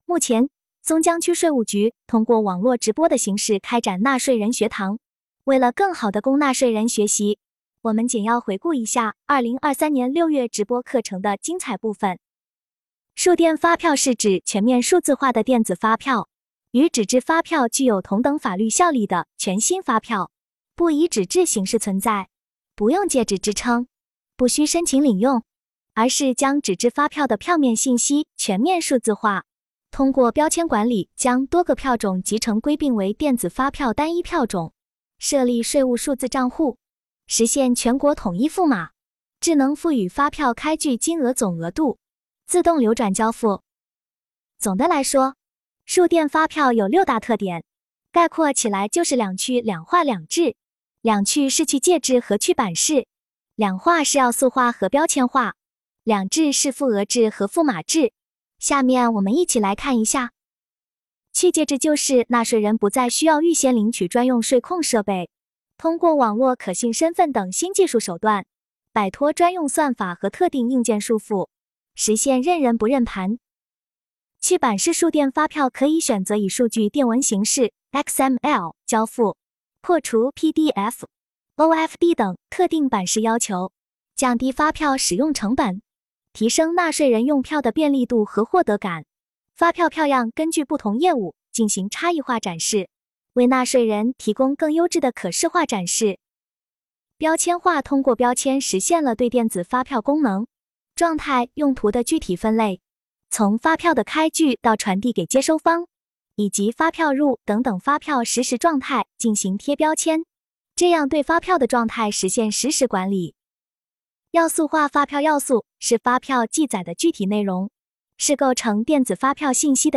目前，松江区税务局通过网络直播的形式开展了纳税人学堂。
直播课程一
根据市局对数字化电子发票开票推广的工作部署，区局逐步推进数字化电子发票开票工作，为尽快让区内企业全面掌握数字化电子发票开票要点，纳税人学堂在6月分批次开展了“数字化电子发票开票培训会”直播。